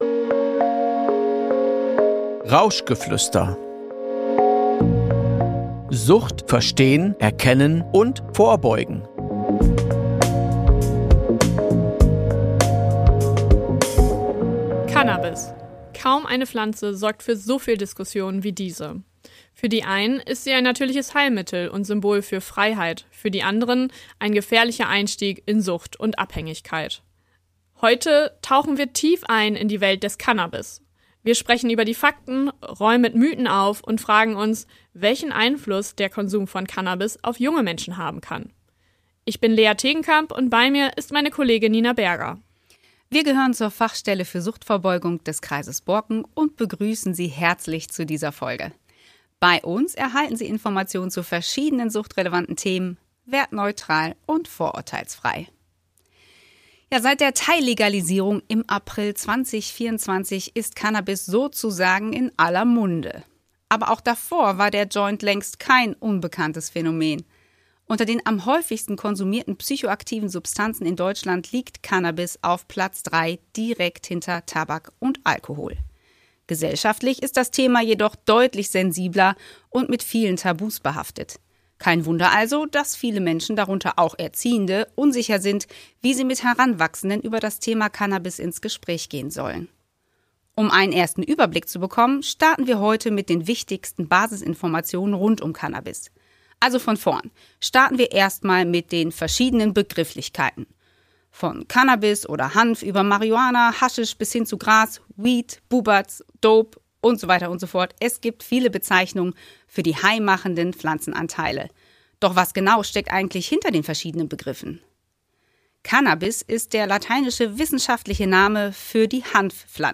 Dabei werfen sie einen besonderen Blick auf die Zielgruppe der Jugendlichen. Von Fakten und Mythen über die neue Gesetzgebung bis hin zu Konsummotiven und Safer-Use Regeln: Erziehende erhalten wertvolle Erkenntnisse und praktische Anregungen, um sich im Cannabis-Dschungel besser zurechtzufinden und mit jungen Menschen ins Gespräch zu gehen. Ein Gespräch, das informiert, einordnet und stärkt – für alle, die mit jungen Menschen im Austausch bleiben wollen.